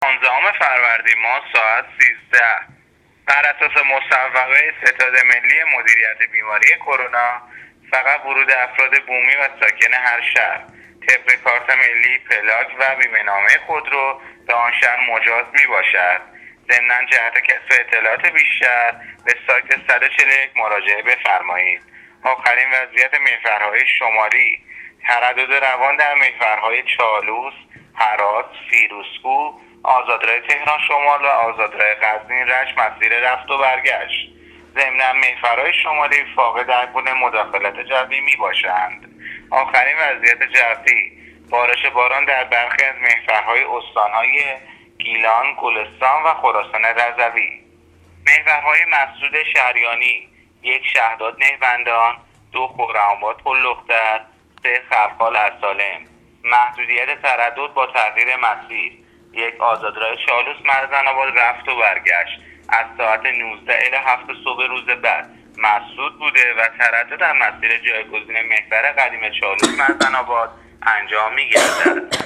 گزارش رادیو اینترنتی از آخرین وضعیت ترافیکی جاده‌ها تا ساعت ۱۳ پانزدهم فروردین